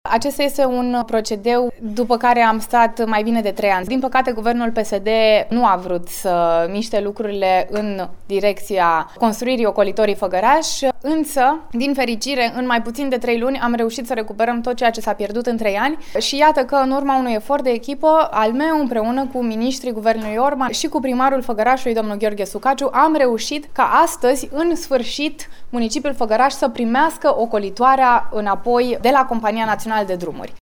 Urmează ca, după transfer, să fie demarată procedura de licitație publică pentru construirea centurii, susţtine Mara Mareș, deputat de Făgăraș.